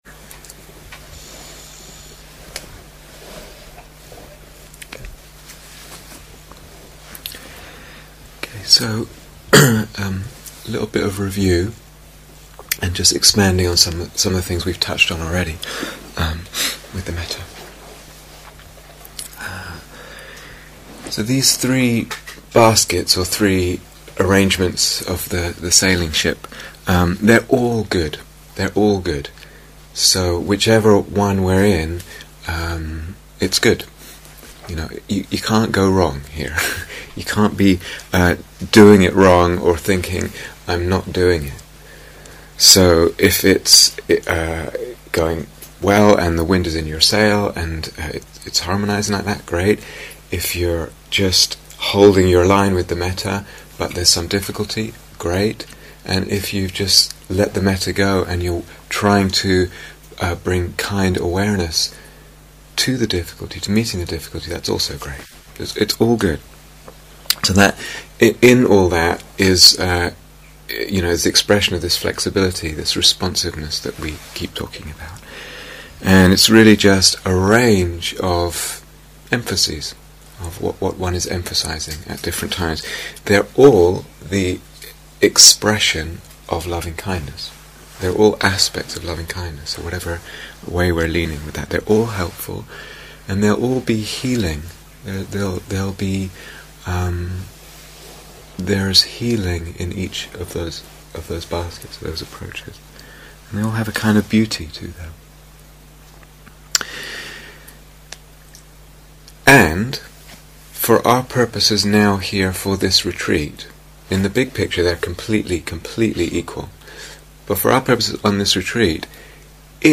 Fourth Instructions and Guided Mettā Meditation